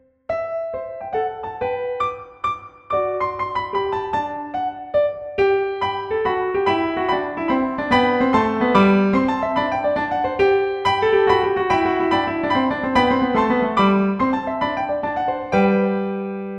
例えば第３楽章の冒頭部、付点がふんだんに交差するリズミカルな箇所でみてみましょう。
軽快にサラッと、左手の付点がつらつらと並んでいますよね。音並びも、同じ型の繰り返しで順ぐり降りてくるパターンです。